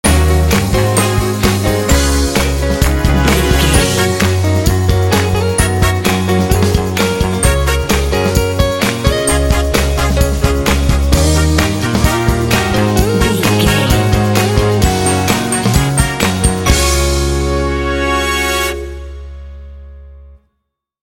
Ionian/Major
energetic
playful
lively
cheerful/happy
piano
trumpet
electric guitar
brass
bass guitar
drums
classic rock